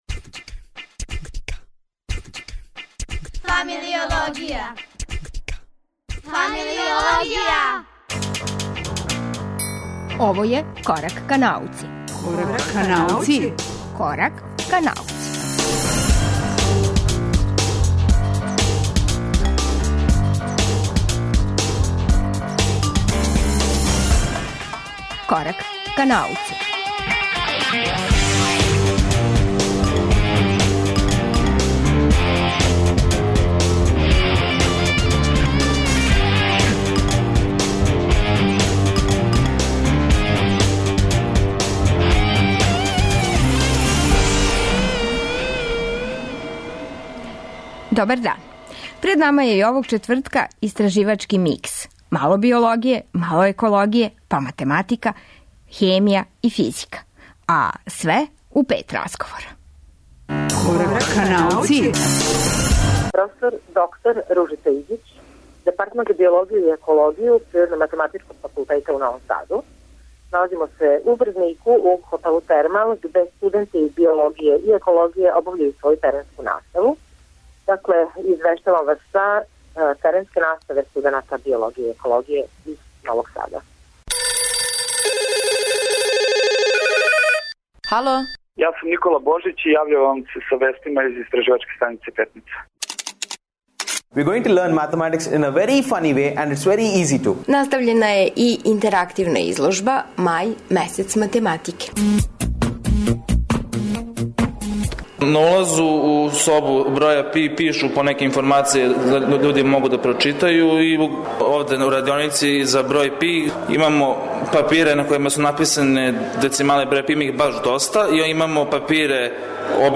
Пред нама је истраживачки микс: мало биологије, мало екологије, математике, хемије и физике! Али све у пет разговора и шест немогућих ствари. То подразумева: пут у ИС Петница; у Врдник међу биологе на теренској пракси; одлазак на изложбу Мај месец математике; форензичку анализу звука и потрагу за новим елементом за који је у периодном систему сачувано место 117.